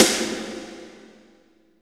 53.03 SNR.wav